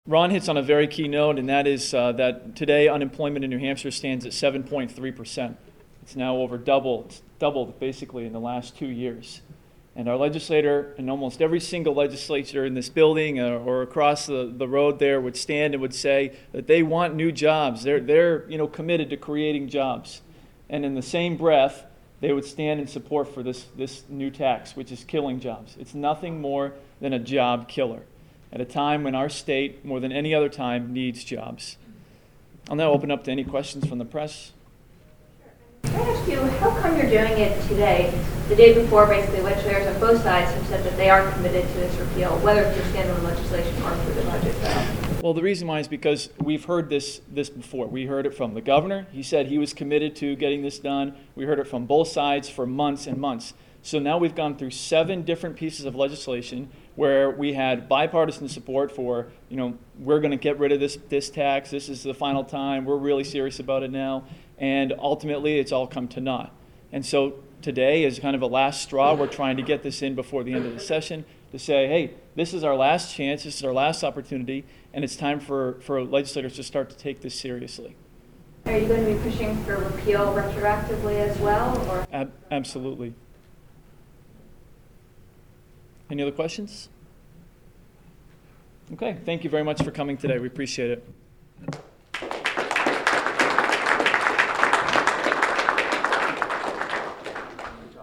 The following audio cuts are soundbytes from the 6/8/10 LLC Tax press conference at the State House and aired on the show: